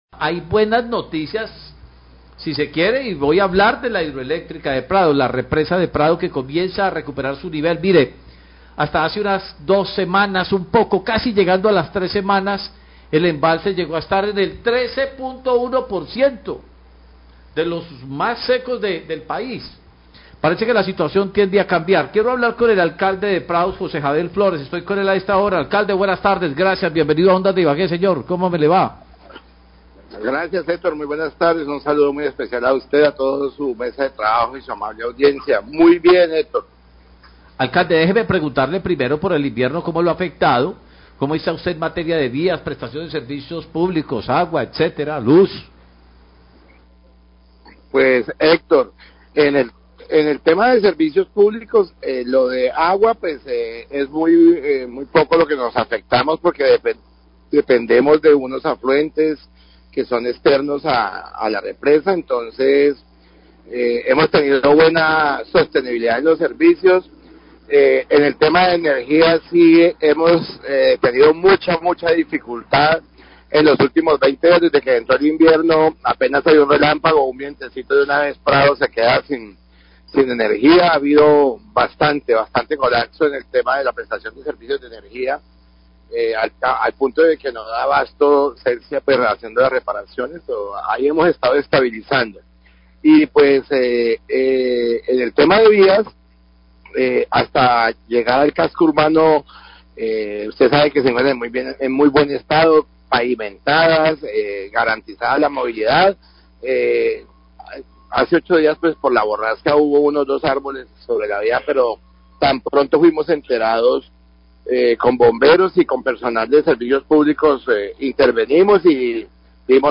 Alcalde de Prado habla del incremento nivel represa de Prado y cortes de energía
Radio